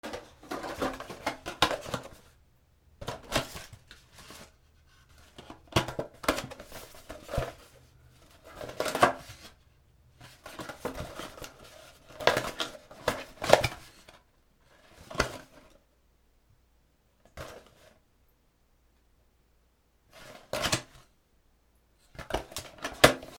厚紙の箱の開け閉め
/ M｜他分類 / L01 ｜小道具 / 文房具・工作道具